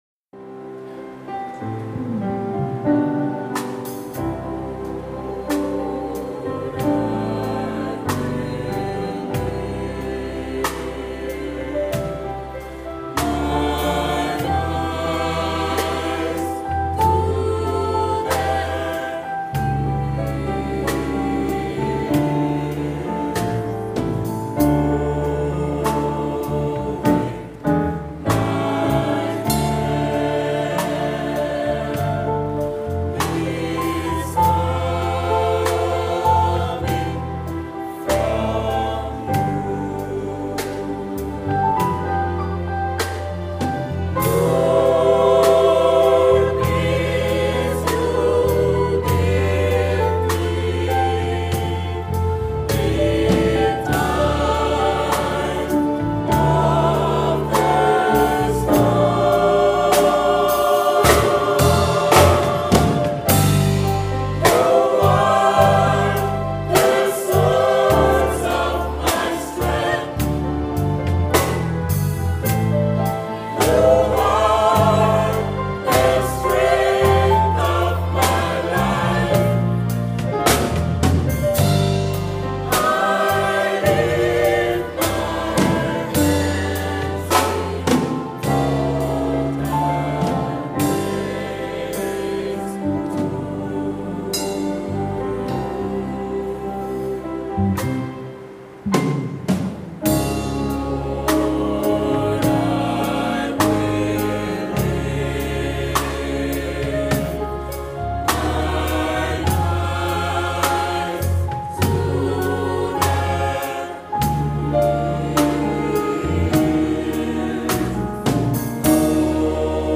coro religioso
Música afrocaribeña: himnos religiosos y marchas fúnebres